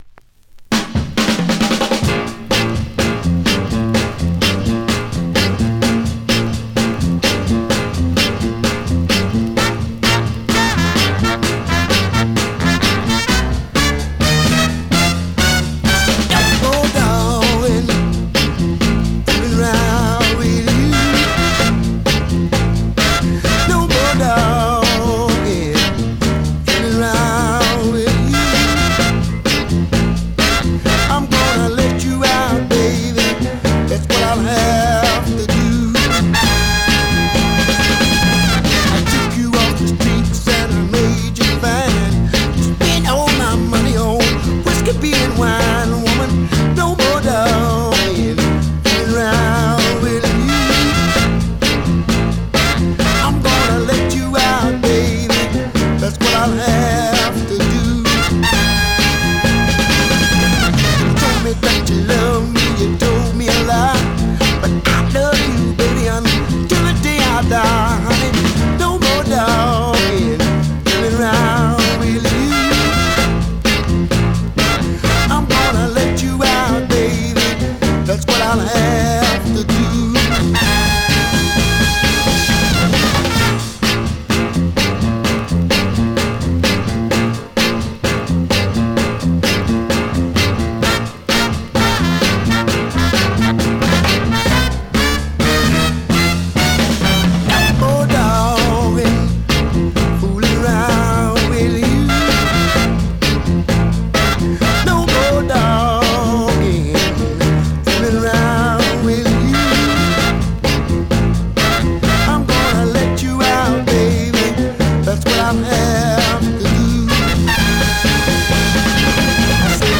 Great dancer from this popular artist
R&B, MOD, POPCORN , SOUL